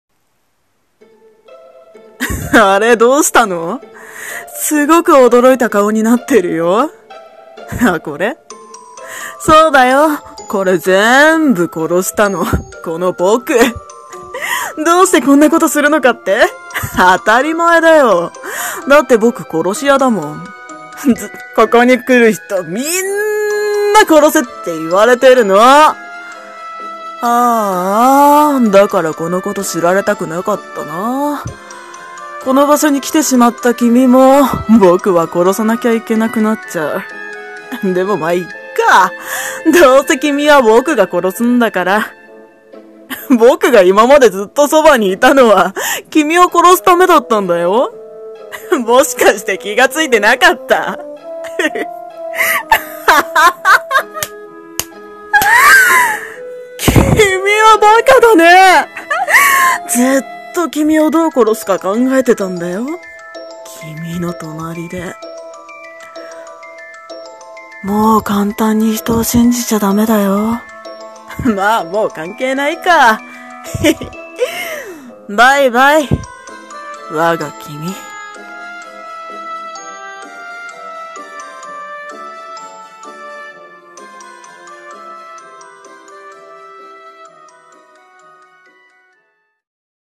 【声劇】 殺し屋のアナタ